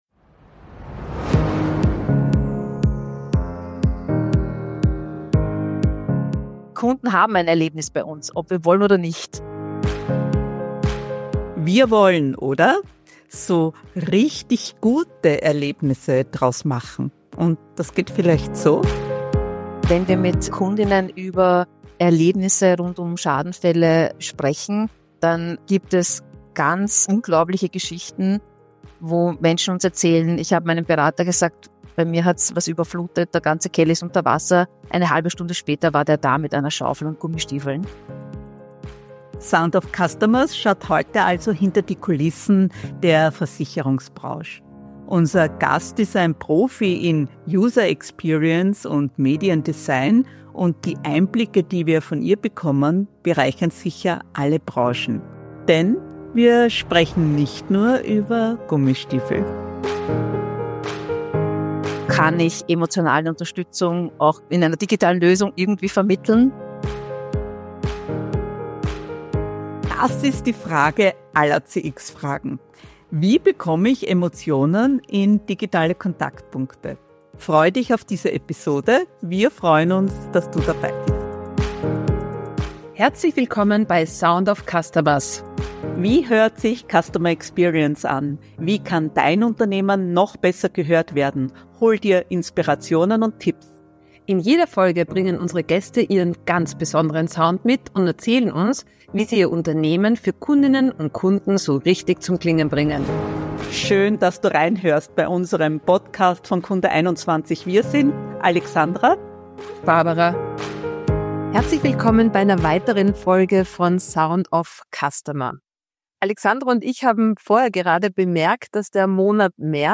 Digitale CX mit Gefühl: Warum Prozesse allein nicht reichen Ein Gespräch